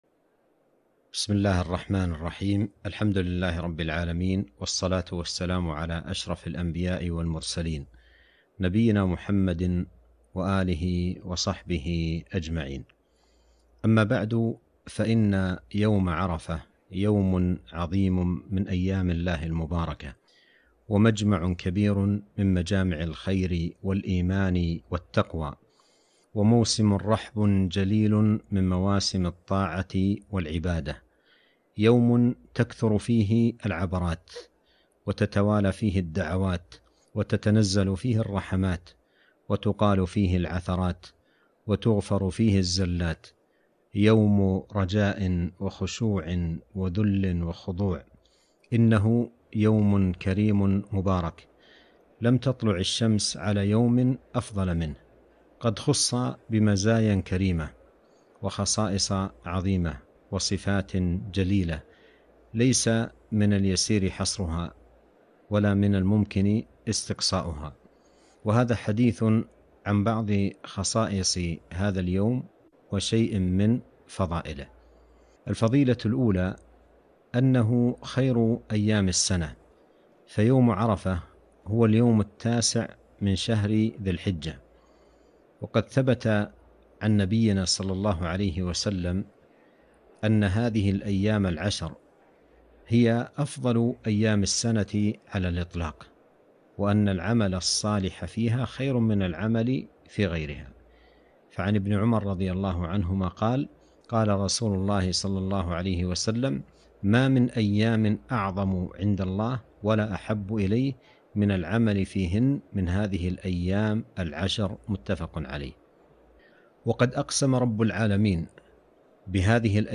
فضائل يوم عرفة - كلمة عبر قناة السنة النبوية ٨ ذو الحجة ١٤٤١هـ